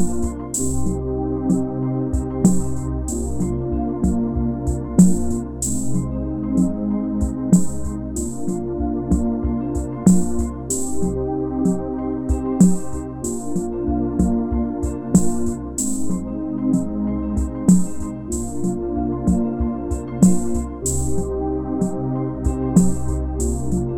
Two Semitones Down Pop (1980s) 4:50 Buy £1.50